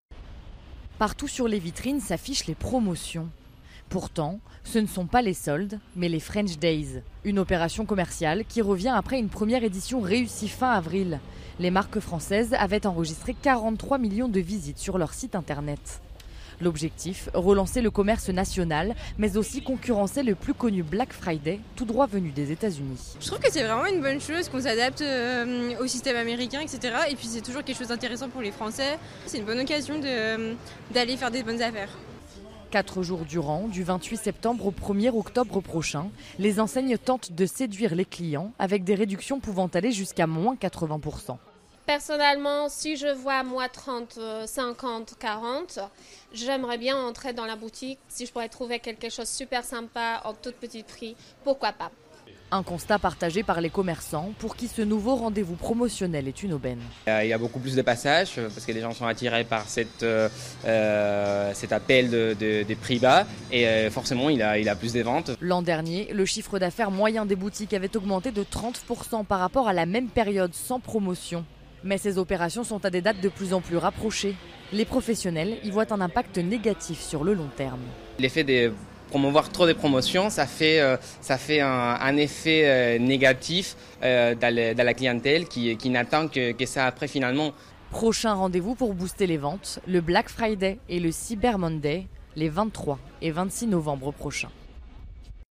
4. Quel est l’avis de la première personne interviewée ?